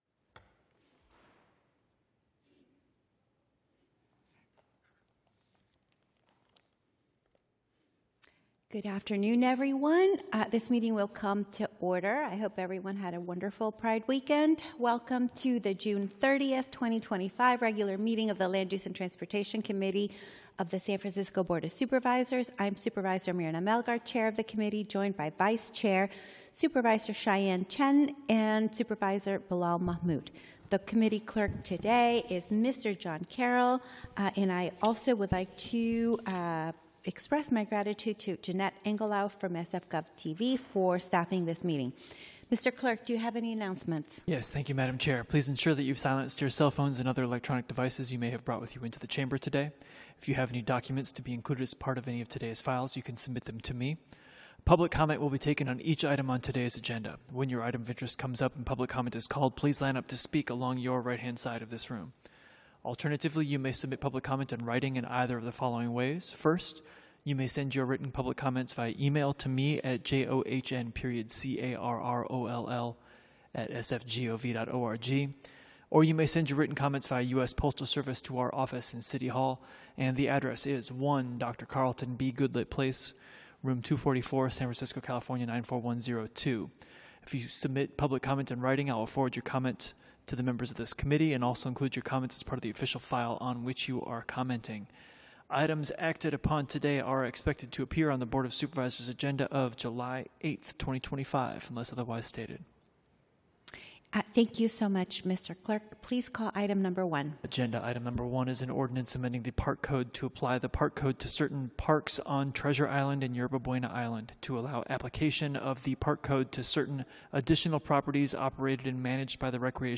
BOS - Land Use and Transportation Committee - Regular Meeting - Jun 30, 2025